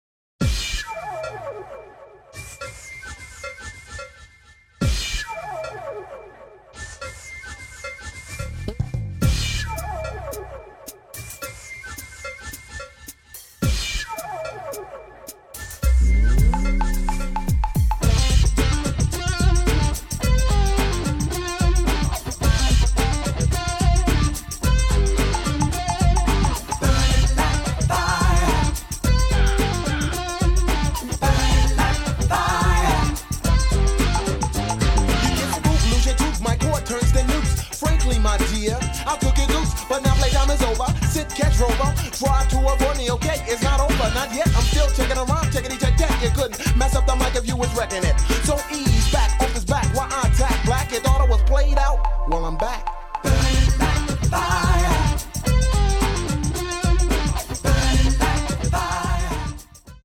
Guitars
Bass
Vocals